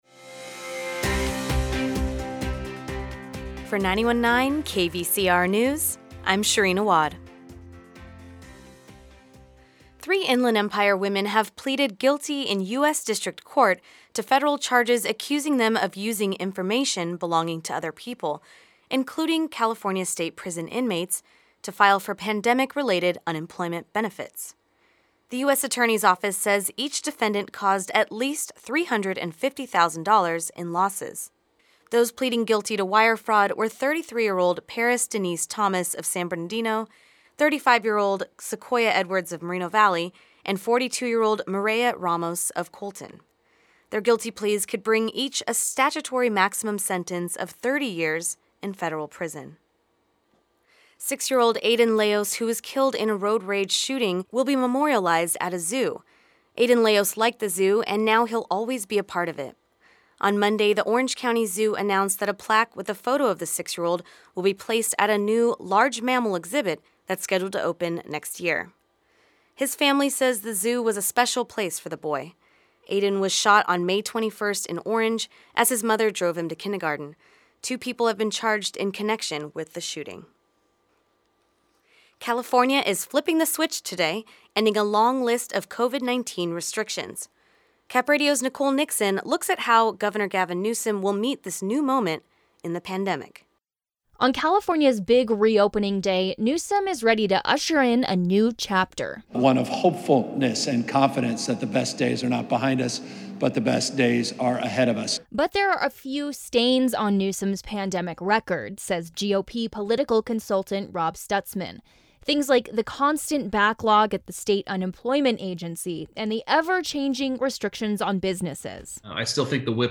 The Midday News Report